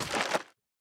1.21.5 / assets / minecraft / sounds / mob / strider / step2.ogg
step2.ogg